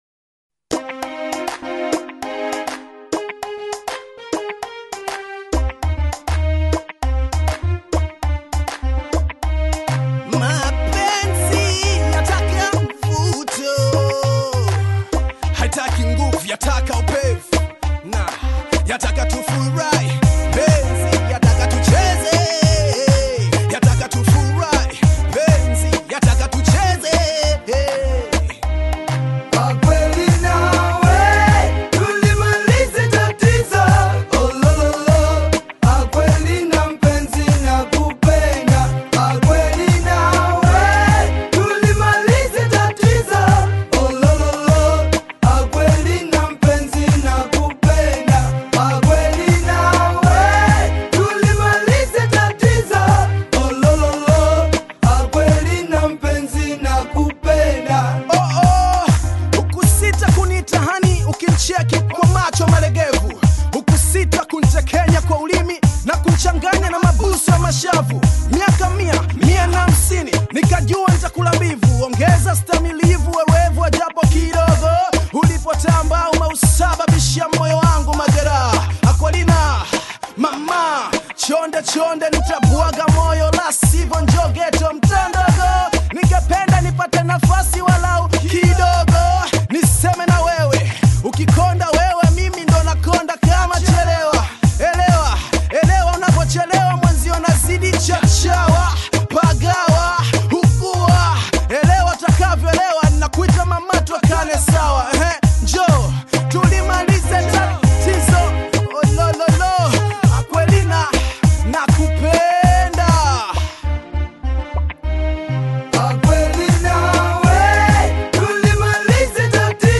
is a vibrant and energetic composition
Bongo Flava style
Filled with catchy beats